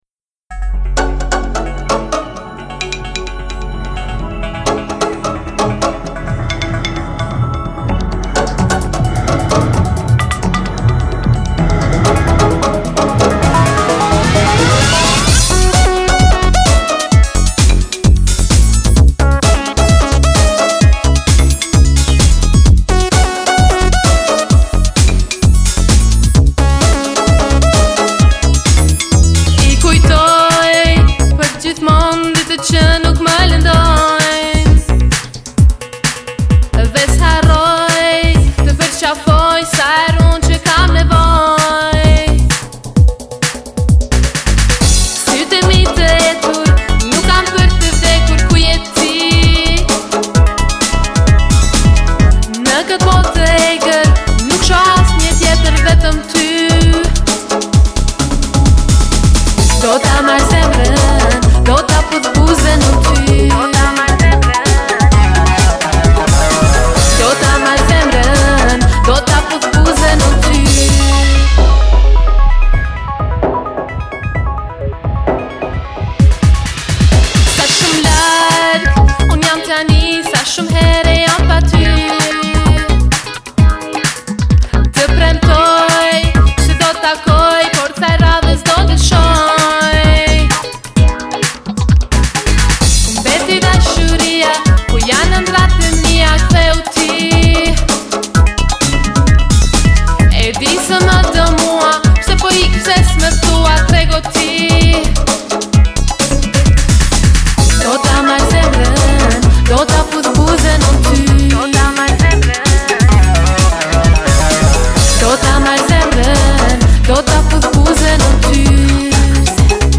(Albanian Female Pop Singer)
Genre: Pop/Dance